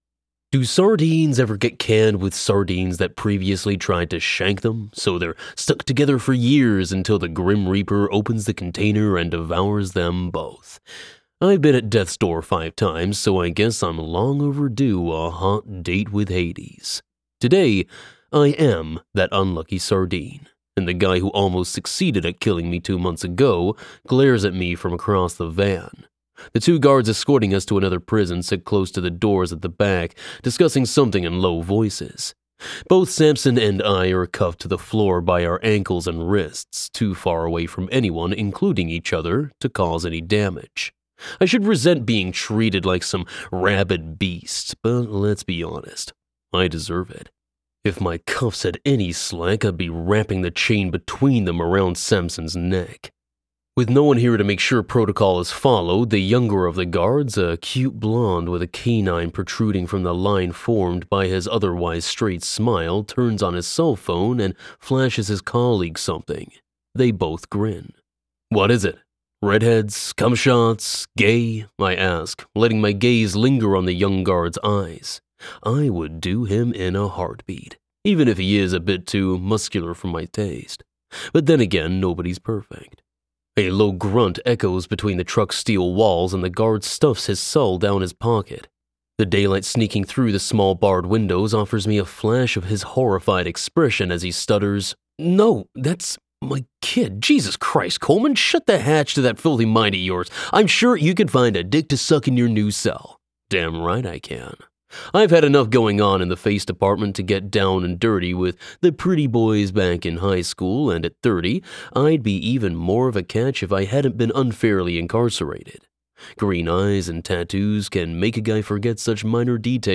English (British)
Yng Adult (18-29) | Adult (30-50)